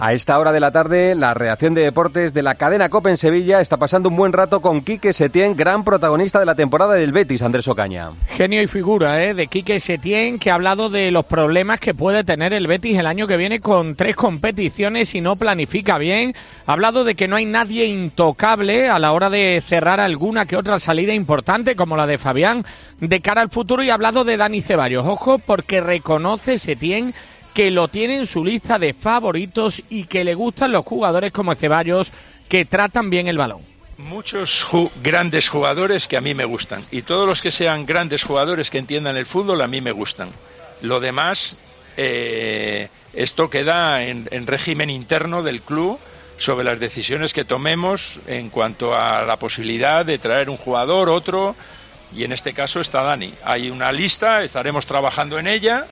El entrenador del Betis aseguró en una entrevista con nuestros compañeros de Deportes COPE Sevilla que el club verdiblanco puede tener problemas la temporada que viene con tres competiciones si no lo planifican bien.